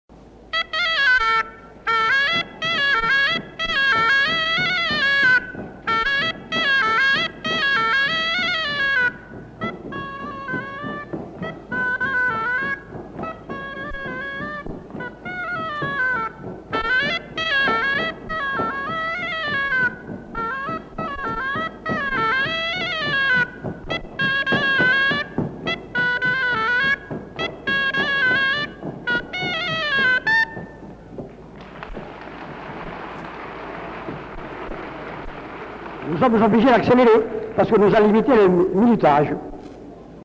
Les Biroussans (ensemble chorégraphique)
Lieu : Montréjeau
Genre : morceau instrumental
Instrument de musique : aboès
Danse : varsovienne